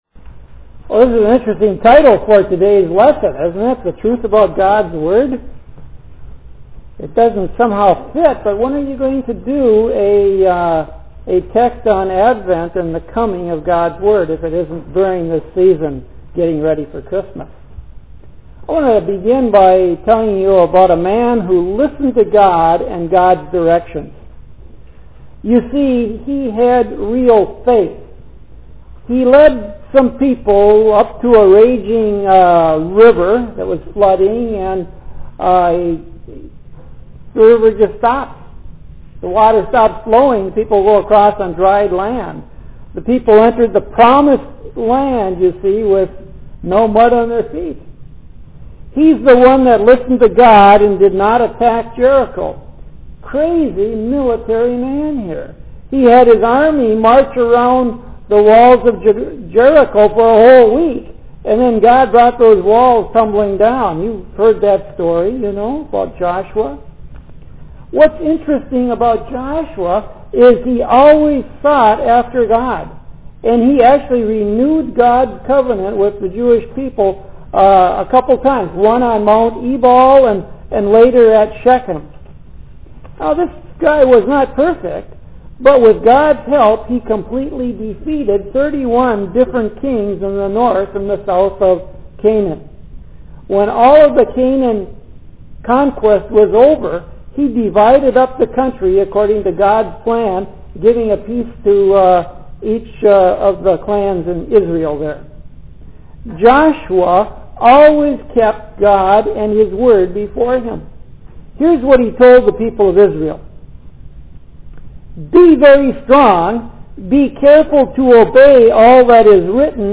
Click here to Listen to a Lesson from Advent 2004